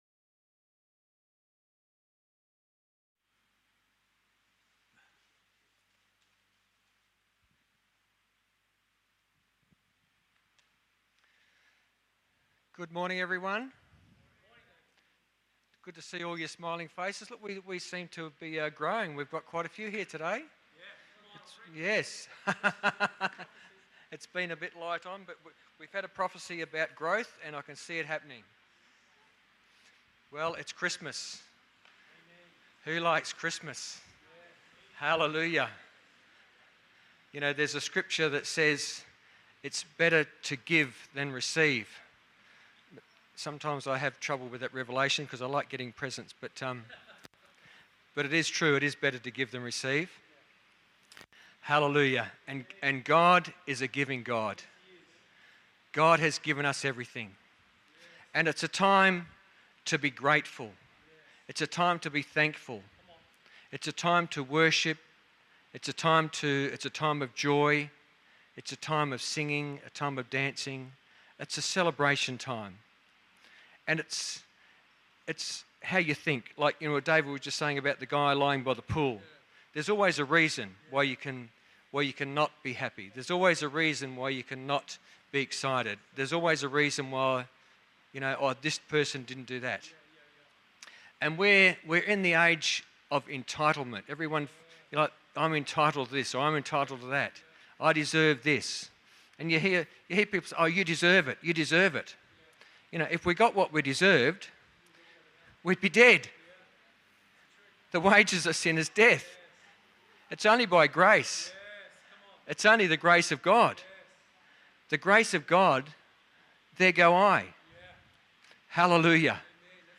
Guest Preaching